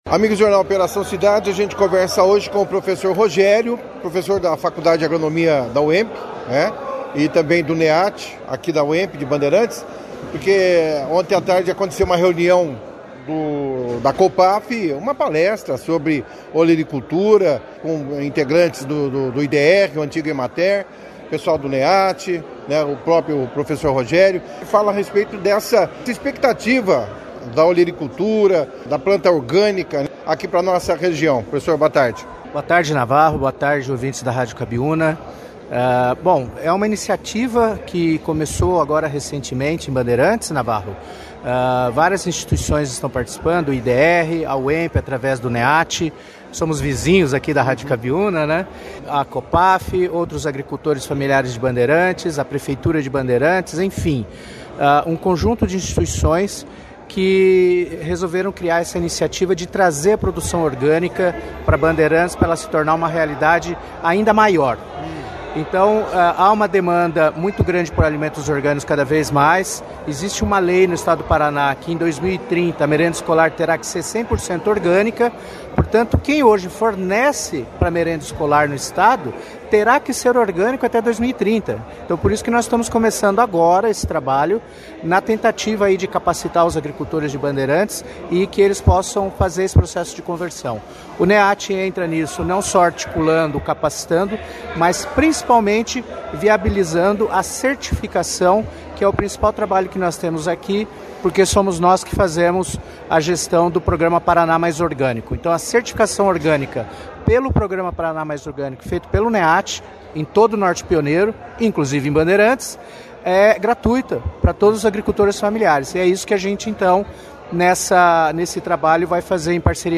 A palestra foi destaque na edição deste sábado do jornal Operação Cidade